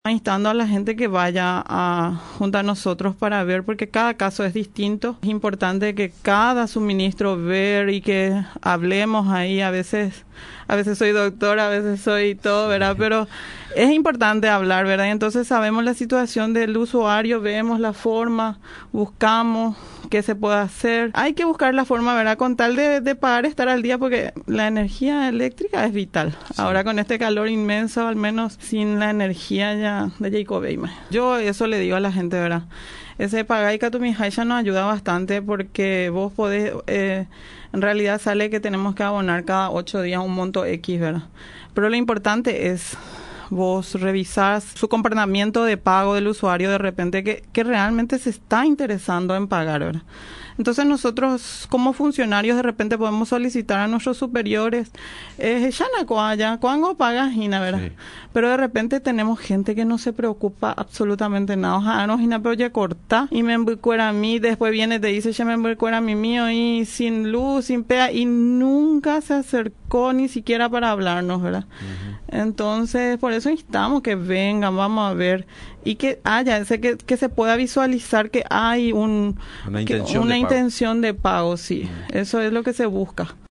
durante su visita a los estudios de Radio Nacional San Pedro.